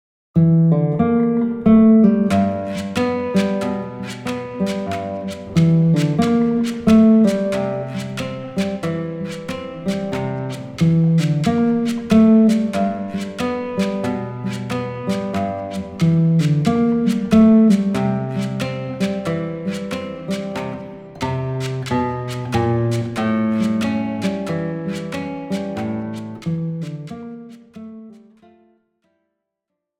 Besetzung: Gitarre